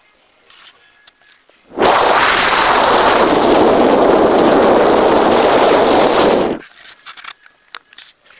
人工合成的Folley " 月球风
描述：我把这个叫做'月球风'，因为它是一个受影响的样本，我认为它听起来像一个非地球的风。我录制了一个真实的风，并用一些重EQ和压缩来处理信号。这个声音是100的连续录音。
标签： 大风 空间 月亮
声道立体声